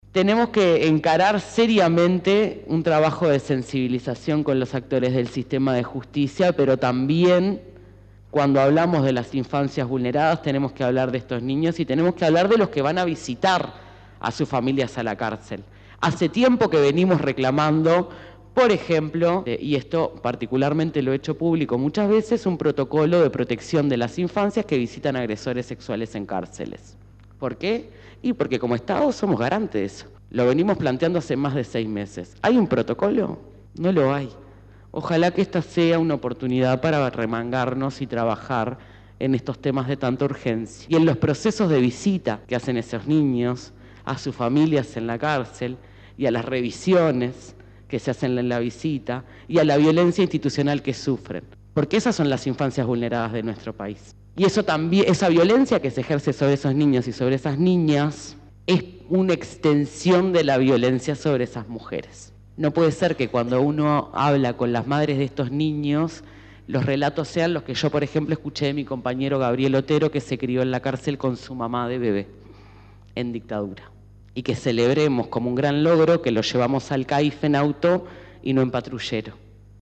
En la presentación de este informe, hicieron uso de la palabra diferentes actores políticos, tanto del gobierno como legisladores del oficialismo y de la oposición.